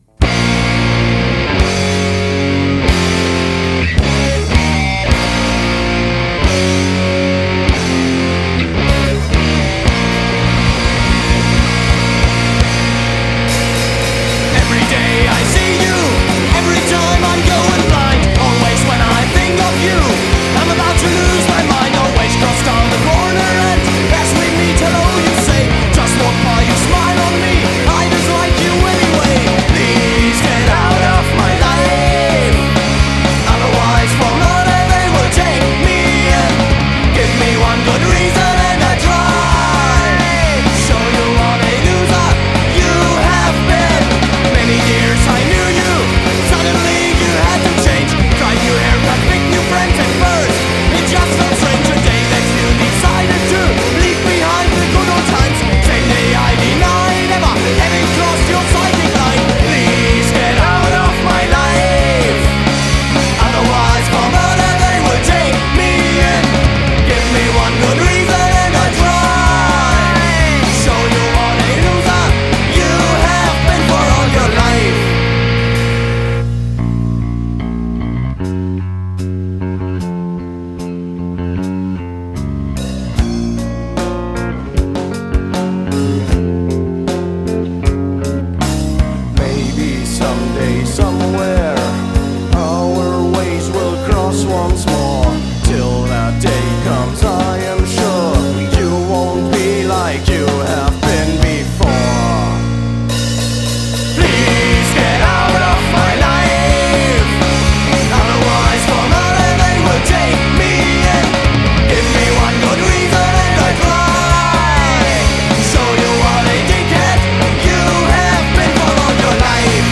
Bass, Bg.Gesang
Schlagzeug
Gitarre
Bass, Schlachzeuch und Gesang.